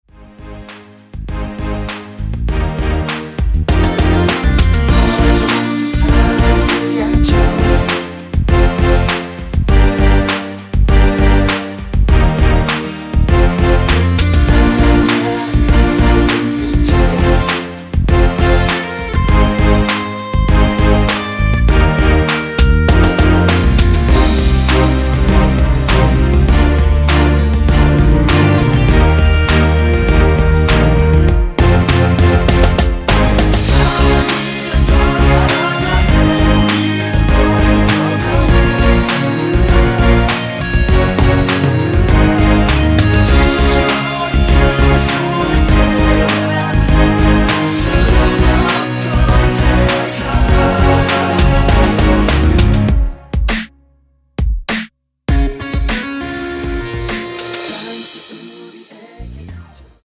원키에서(-1)내린 코러스 포함된 MR입니다.(미리듣기 참조)
Bb
앞부분30초, 뒷부분30초씩 편집해서 올려 드리고 있습니다.
중간에 음이 끈어지고 다시 나오는 이유는